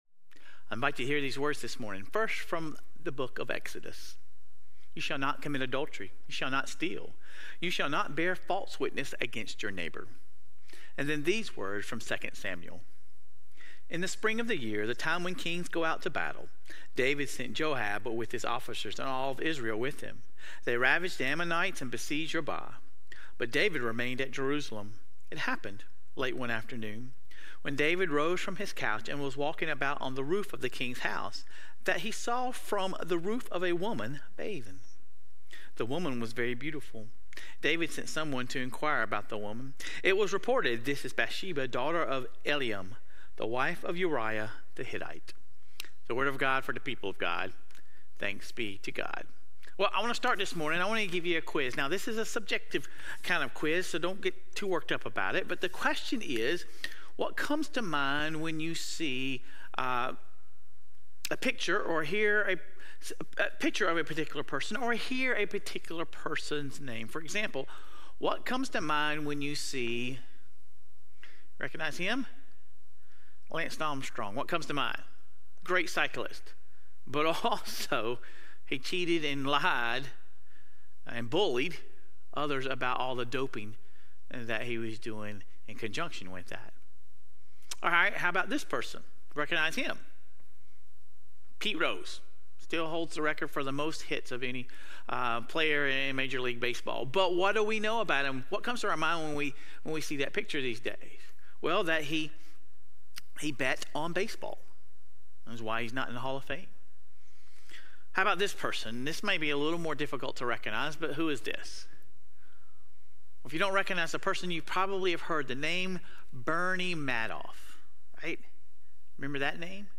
Sermon Reflections: The sermon discusses David's "moment of the maybe" — a time when he could have said no to temptation but instead entertained it.